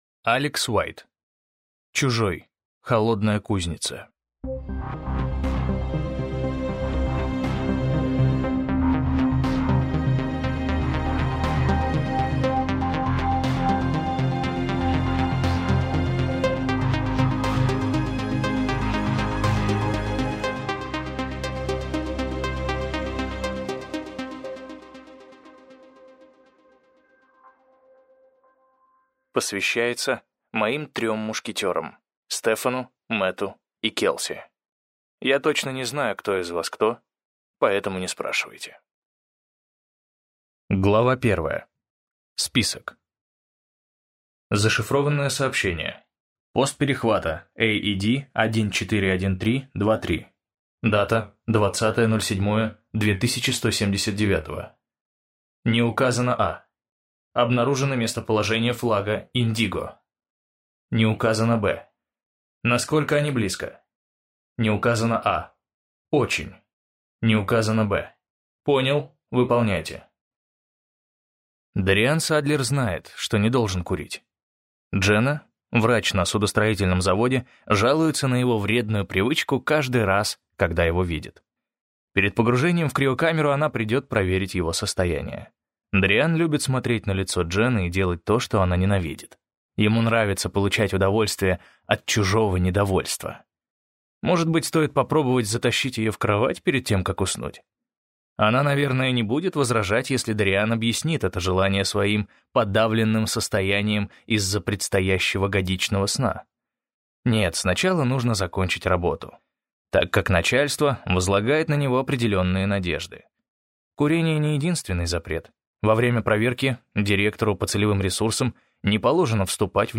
Прослушать фрагмент аудиокниги Чужой: Холодная кузница Алекс Уайт Произведений: 1 Скачать бесплатно книгу Скачать в MP3 Вы скачиваете фрагмент книги, предоставленный издательством